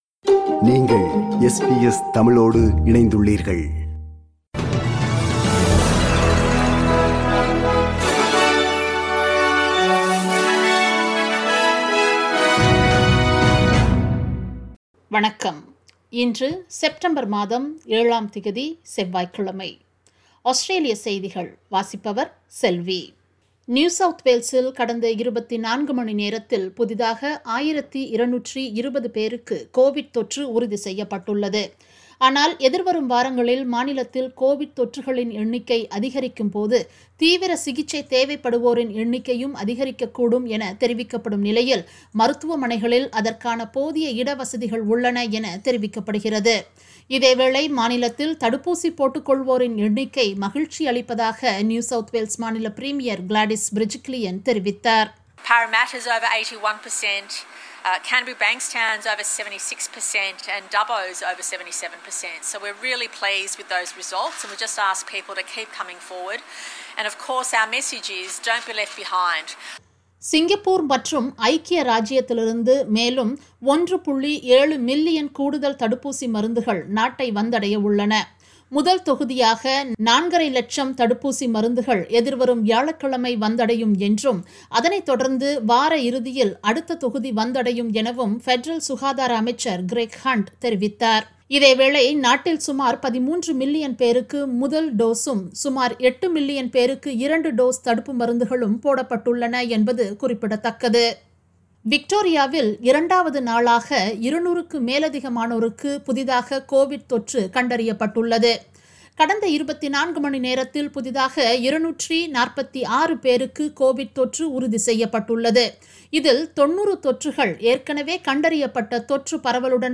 Australian news bulletin for Tuesday 07 September 2021.